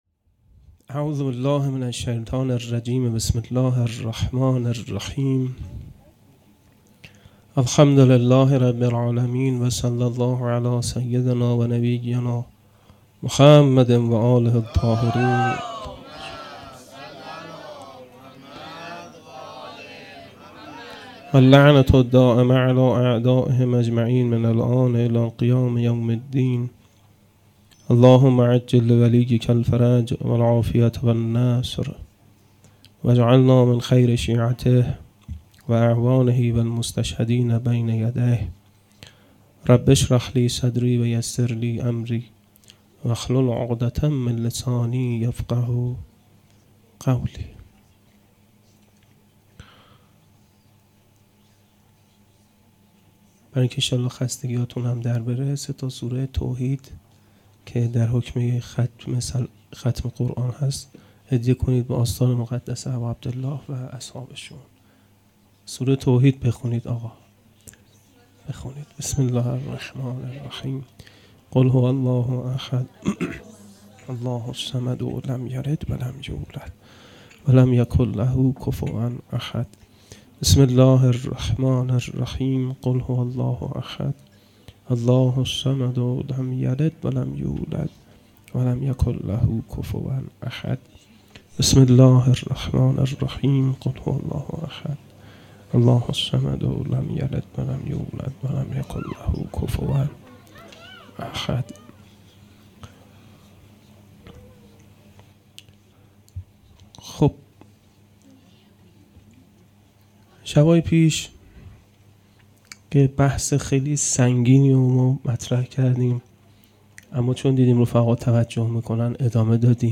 شب پنجم محرم الحرام 1441